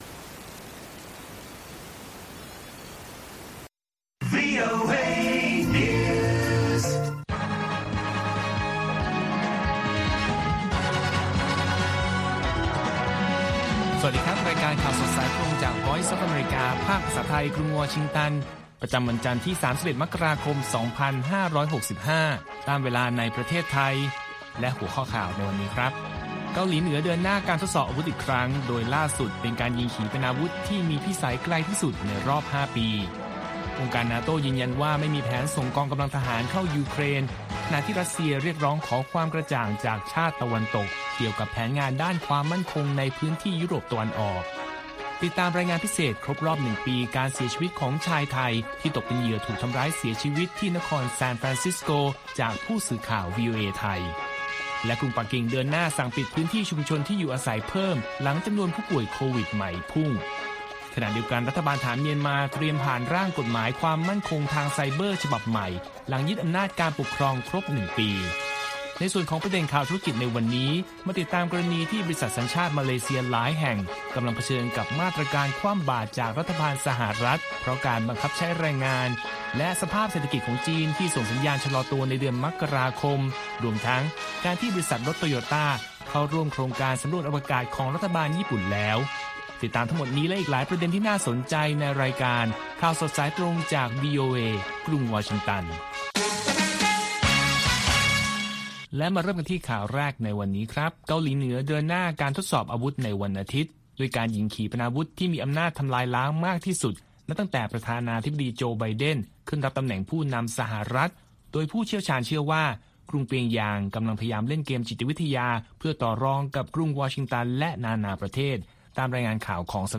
ข่าวสดสายตรงจากวีโอเอ ภาคภาษาไทย 8:30–9:00 น. ประจำวันจันทร์ที่ 31 มกราคม 2565 ตามเวลาในประเทศไทย